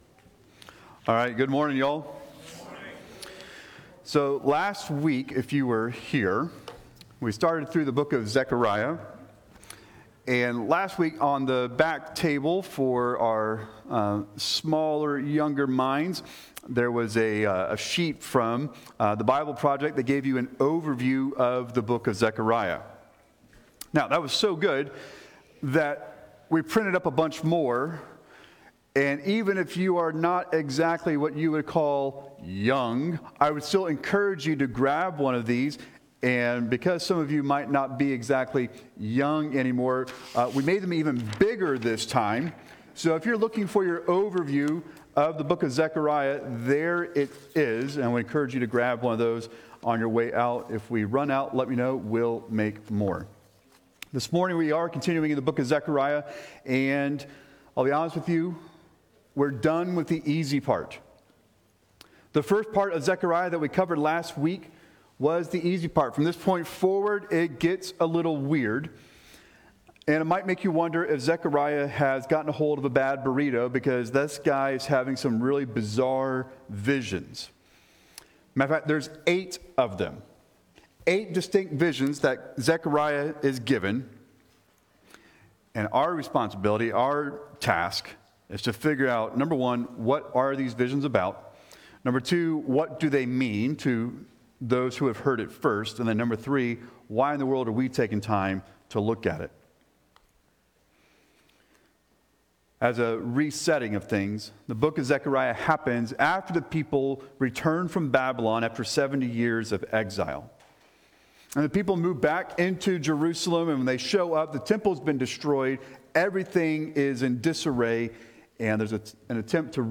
Sermons | Machias Community Church